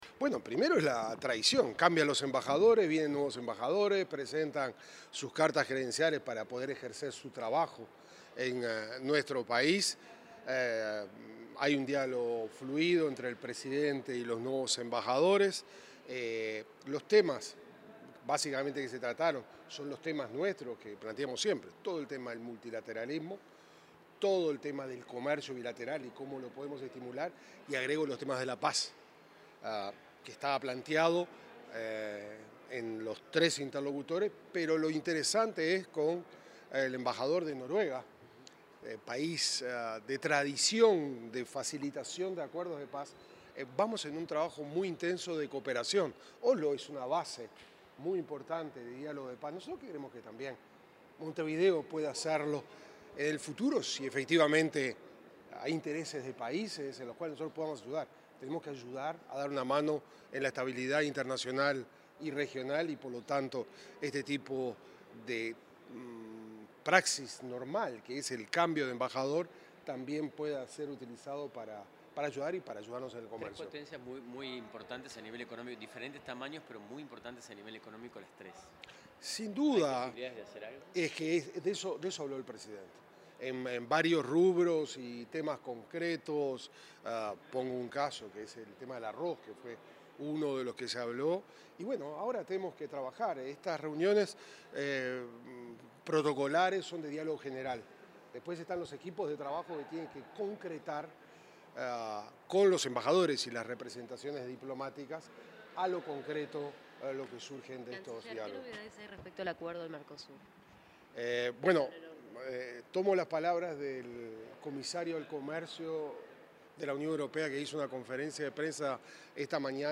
Declaraciones del canciller Mario Lubetkin
El ministro de Relaciones Exteriores, Mario Lubetkin, realizó declaraciones a la prensa sobre la presentación de cartas credenciales, el avance de las